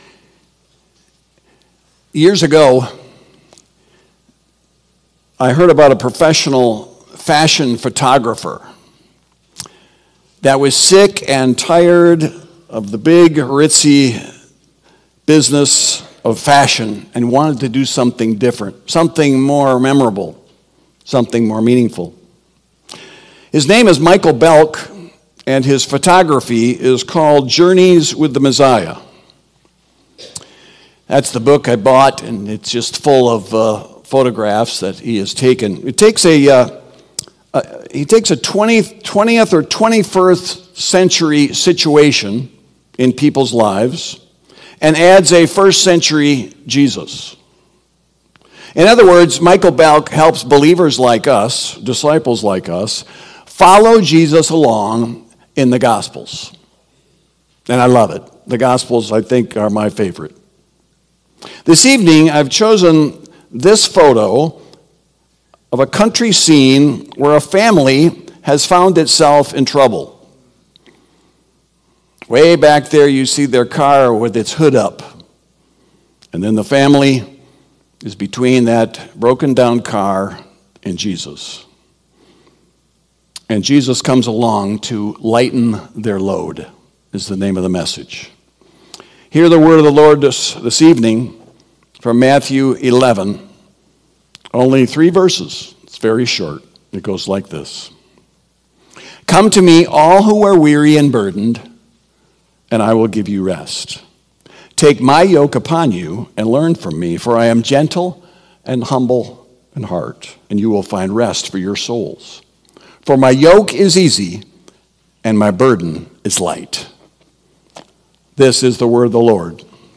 Sermon Recordings | Faith Community Christian Reformed Church
“Lighten the Load” March 9 2025, P.M. Service